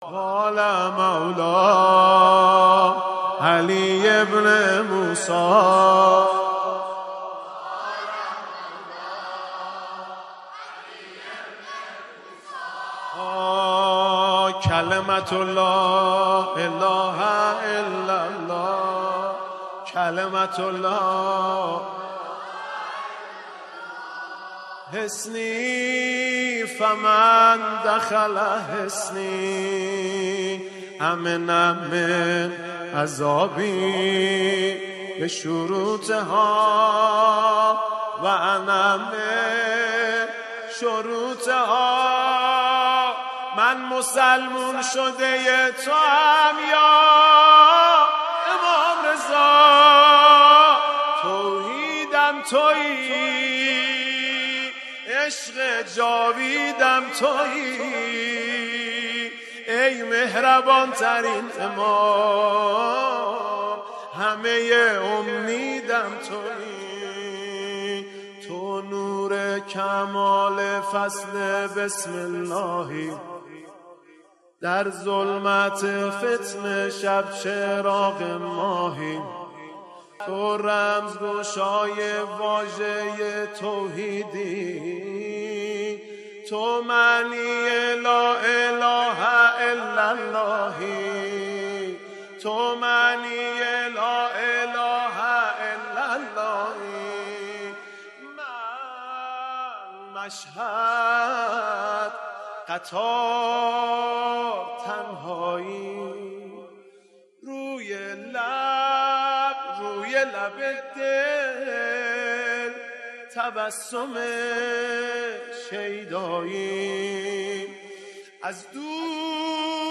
با صدای دلنشین